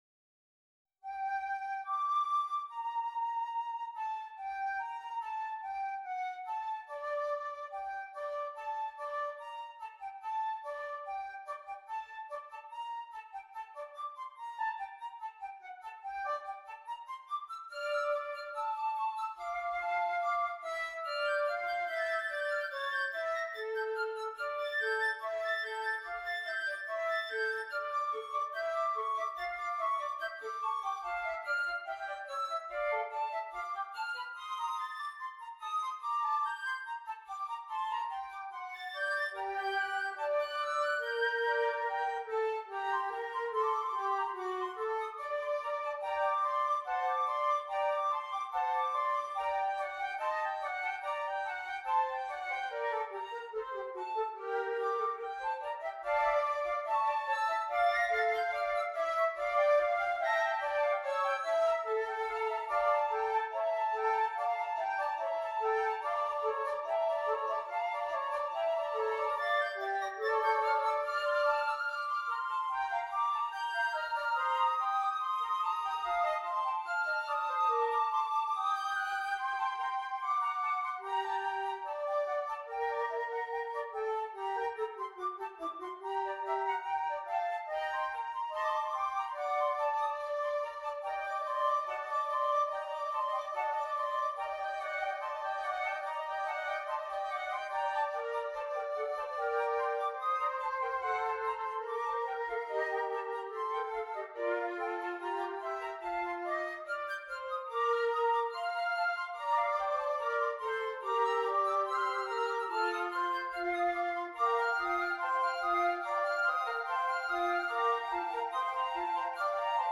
6 Flutes